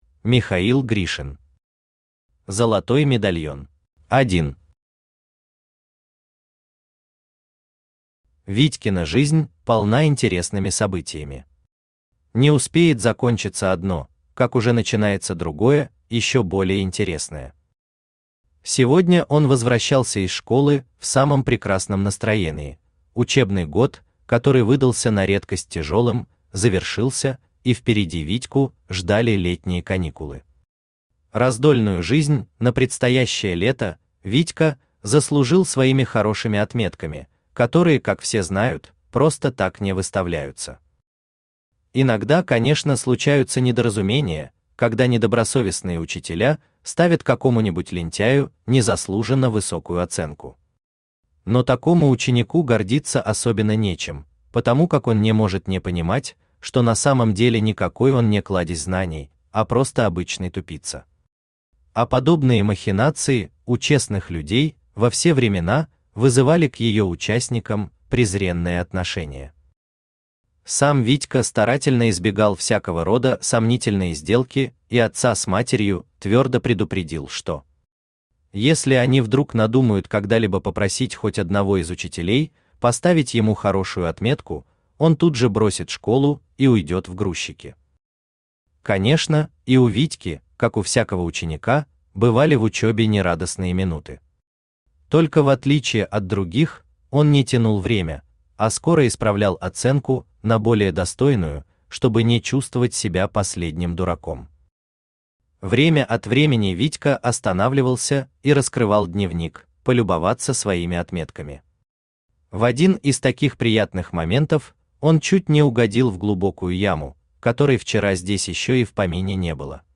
Аудиокнига Золотой медальон | Библиотека аудиокниг
Aудиокнига Золотой медальон Автор Михаил Анатольевич Гришин Читает аудиокнигу Авточтец ЛитРес.